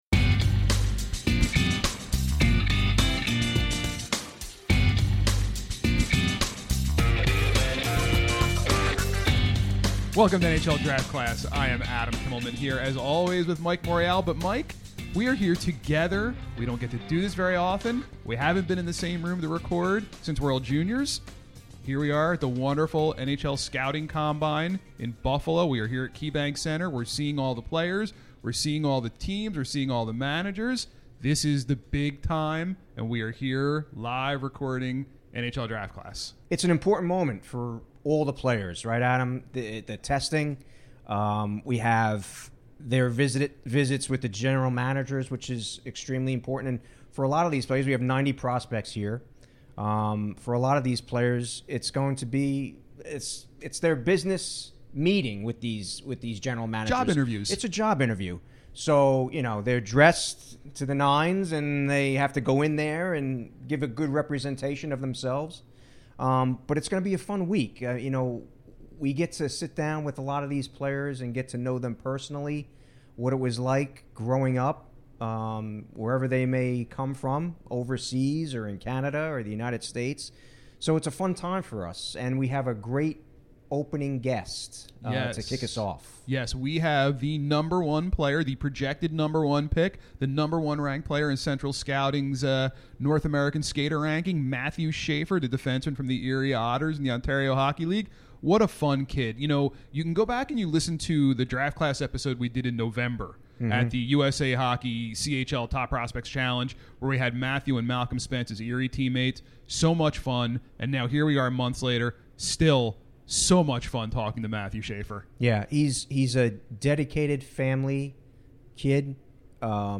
NHL Draft Class is in Buffalo for the NHL Scouting Combine